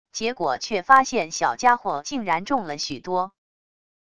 结果却发现小家伙竟然重了许多wav音频生成系统WAV Audio Player